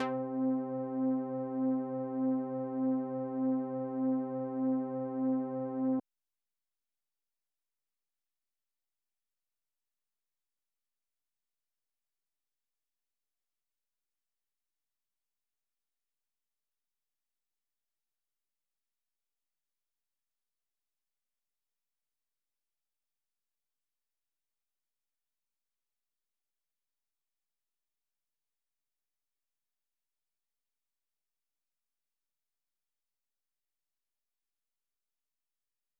Synths